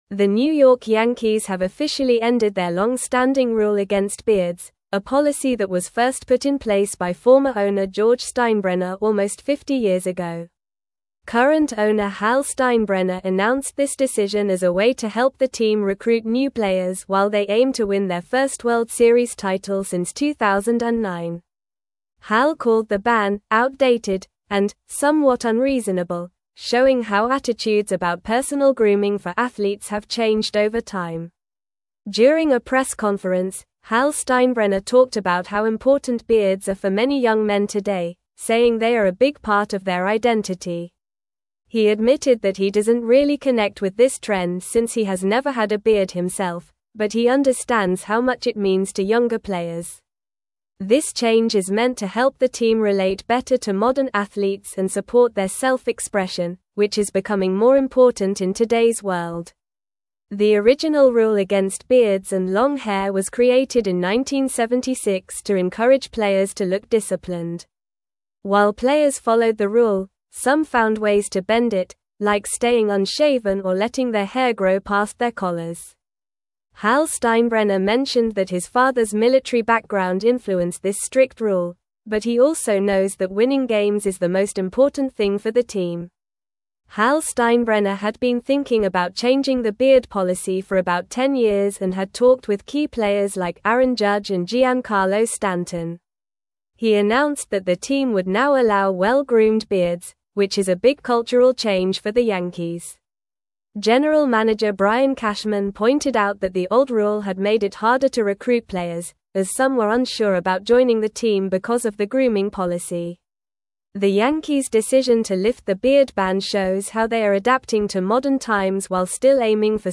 Normal
English-Newsroom-Upper-Intermediate-NORMAL-Reading-Yankees-Lift-Longstanding-Beard-Ban-for-Players.mp3